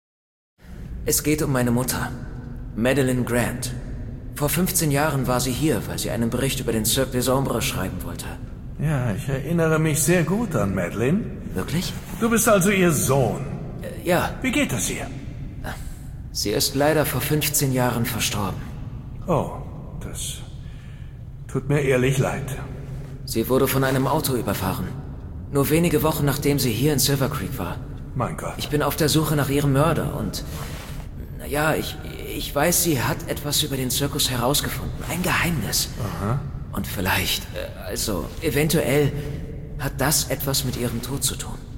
Klar, markant, frisch, wandelbar, witzig, sinnlich.